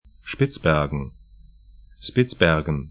Spitzbergen 'ʃpɪtsbɛrgən